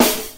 • 90s Jazz Acoustic Snare Sound G# Key 10.wav
Royality free snare single hit tuned to the G# note. Loudest frequency: 4197Hz
90s-jazz-acoustic-snare-sound-g-sharp-key-10-K1g.wav